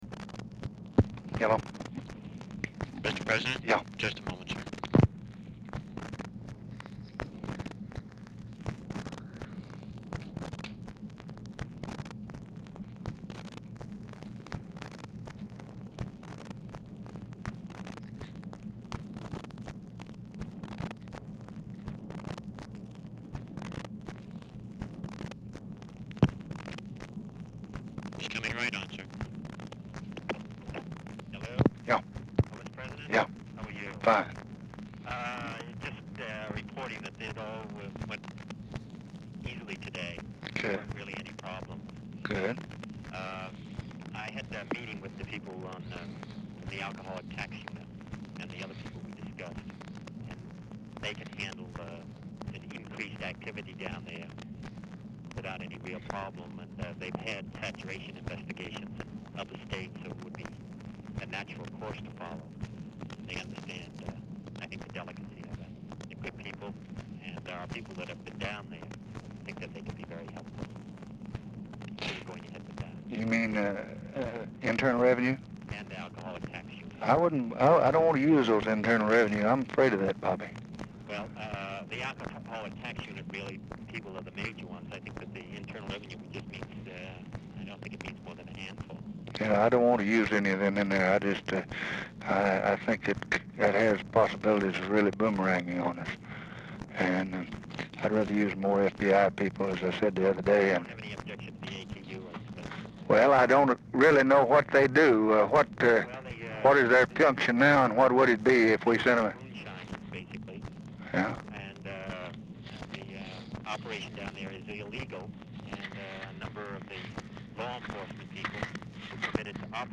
LBJ Ranch, near Stonewall, Texas
Telephone conversation
Dictation belt